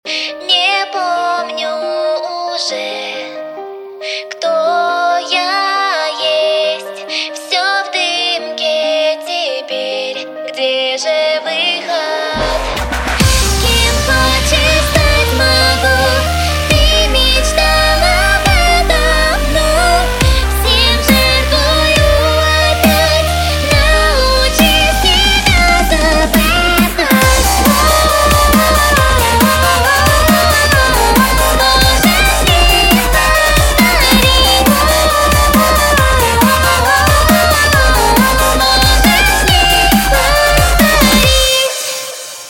• Качество: 128, Stereo
Electronic
Melodic dubstep
Cover
красивый женский голос